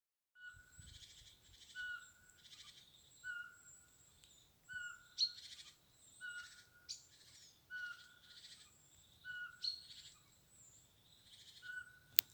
Птицы -> Совообразные ->
воробьиный сыч, Glaucidium passerinum
СтатусПоёт